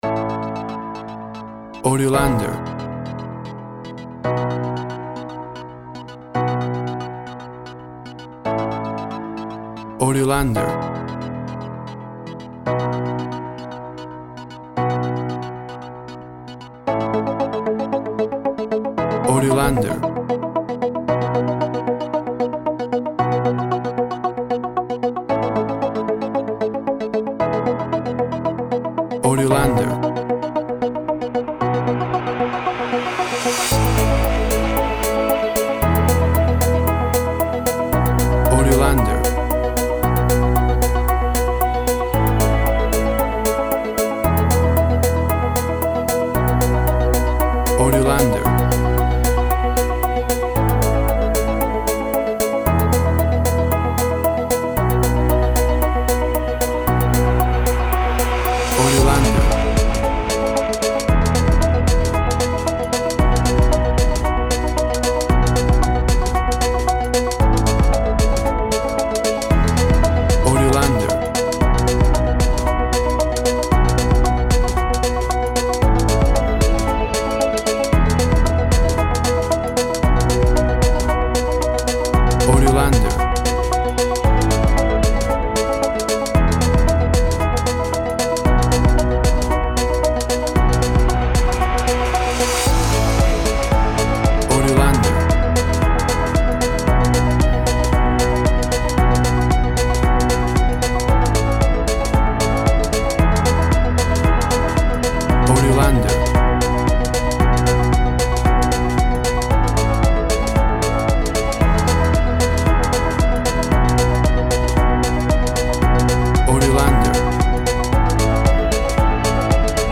WAV Sample Rate 16-Bit Stereo, 44.1 kHz
Tempo (BPM) 120